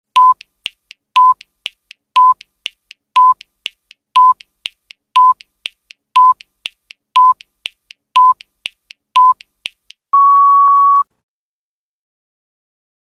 دانلود آهنگ ثانیه شمار 5 از افکت صوتی اشیاء
جلوه های صوتی